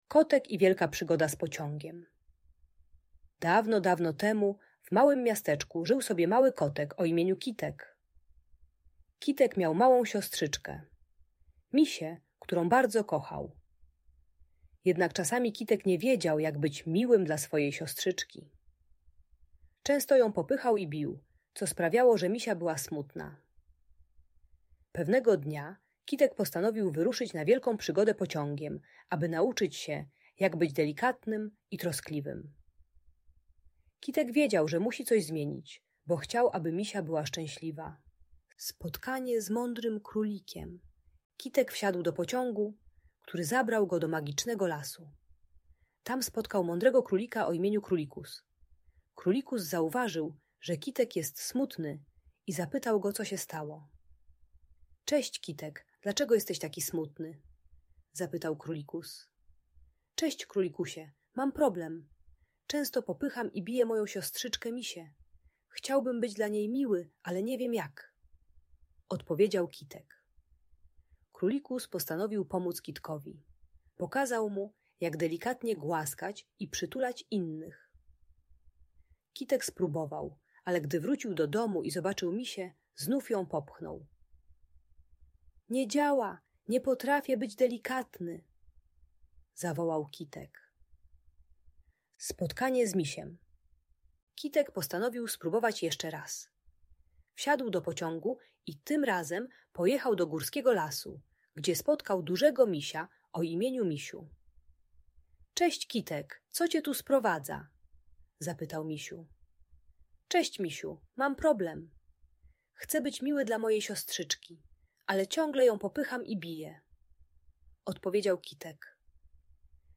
Historia o Kitku i jego wielkiej przygodzie - Bunt i wybuchy złości | Audiobajka